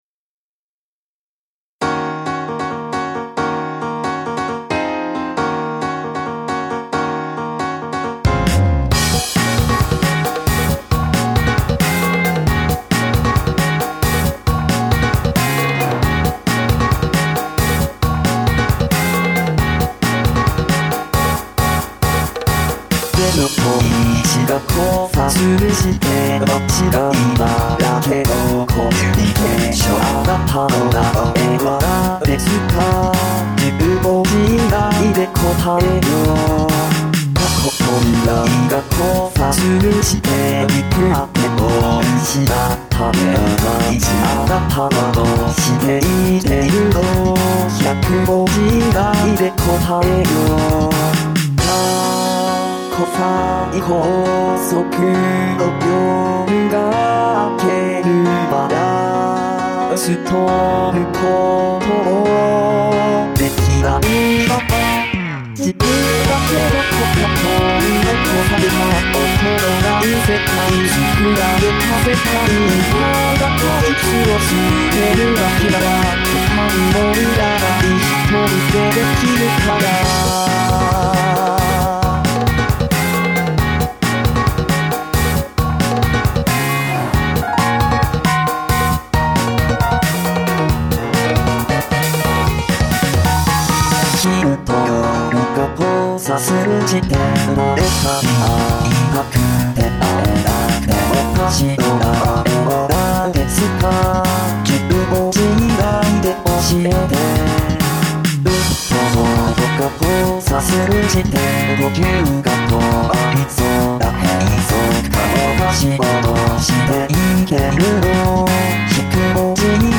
声の違いがすでに中の人しかわからないレベルですが･･･